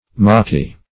maki - definition of maki - synonyms, pronunciation, spelling from Free Dictionary Search Result for " maki" : The Collaborative International Dictionary of English v.0.48: Maki \Ma"ki\, n. [F., from native name.]